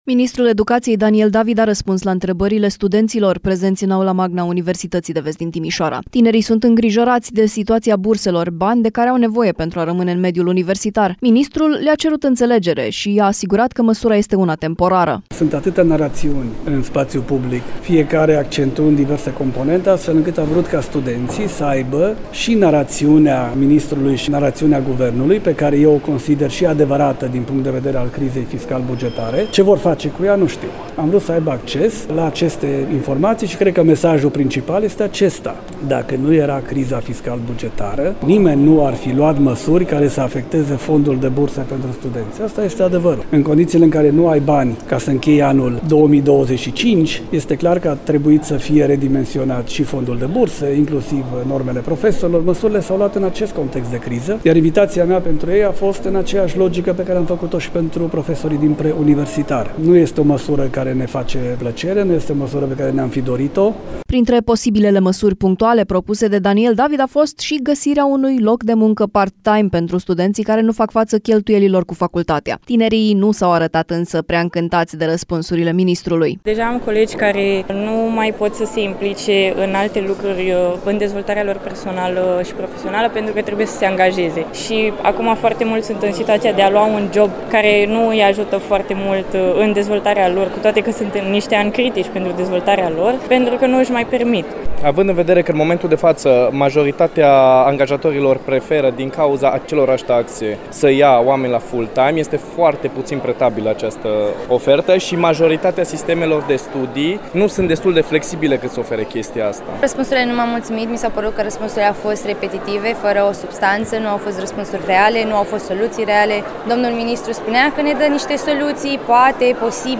Ministrul Educației Daniel David a răspuns la întrebările studenților prezenți în Aula Magna Universității de Vest din Timișoara.